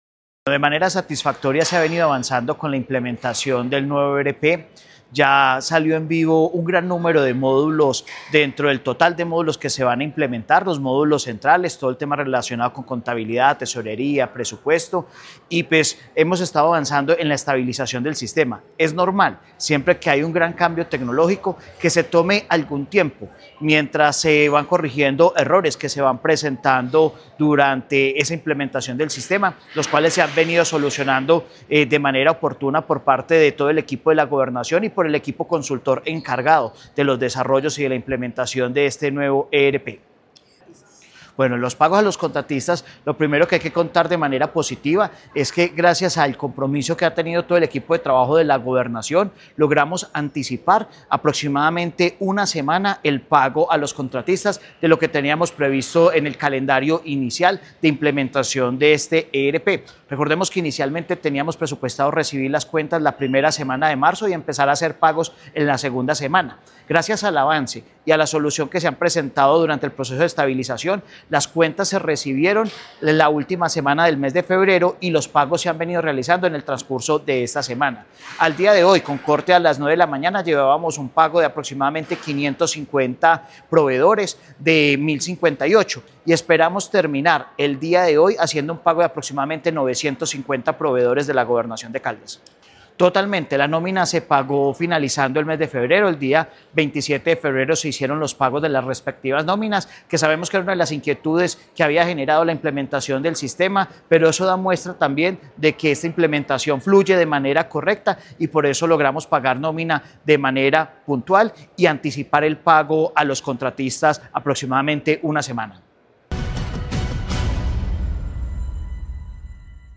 Secretario de Hacienda de Caldas, John Alexander Alzate.
John-Alexander-Alzate-Secretario-de-Hacienda.mp3